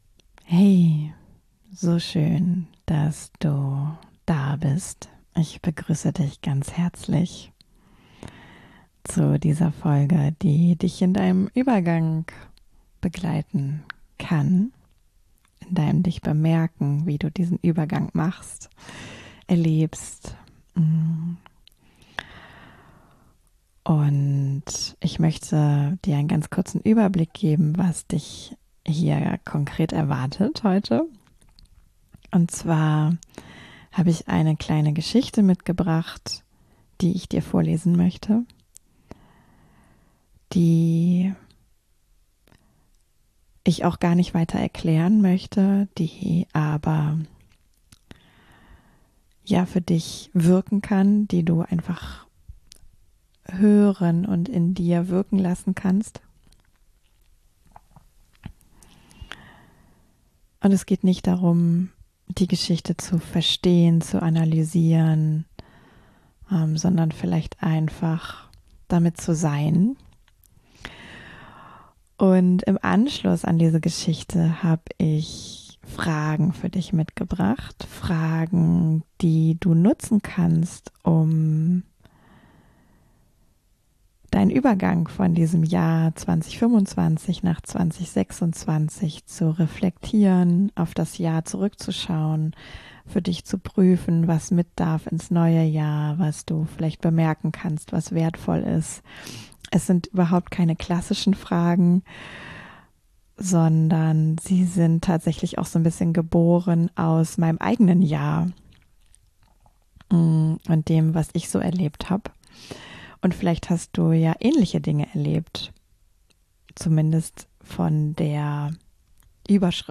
Ich lese dir eine kurze buddhistische Geschichte vor über Entwicklung und den Moment, in dem wir beginnen, neu zu wählen....